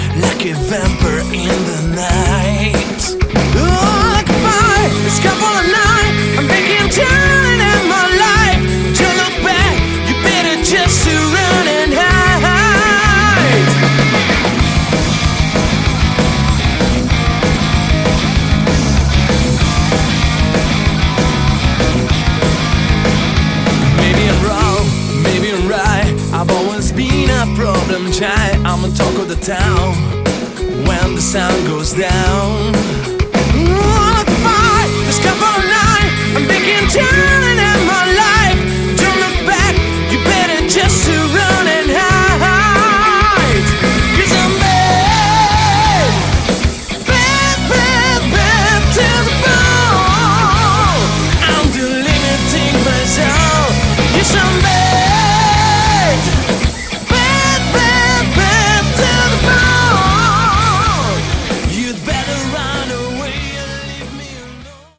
Category: Hard Rock
Vocals
Guitar
Bass
Drum